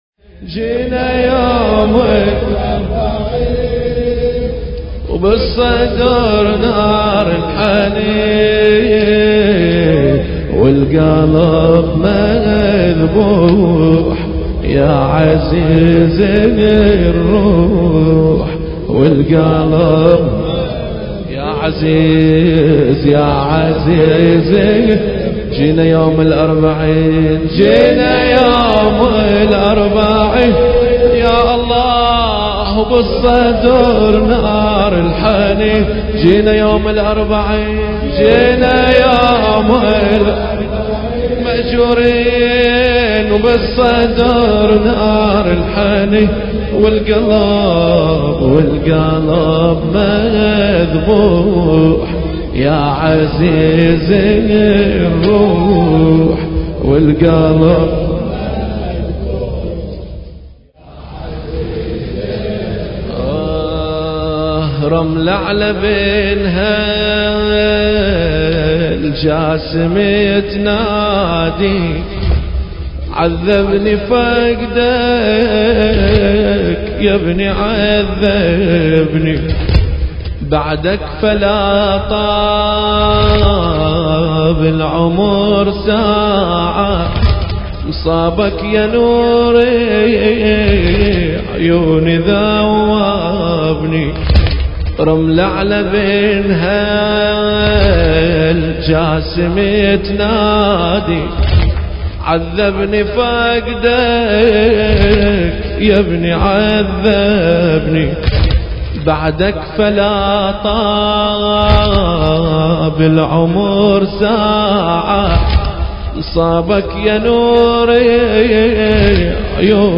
المراثي
الحجم: 4.18 MB المكان: موكب عزاء النعيم الموحد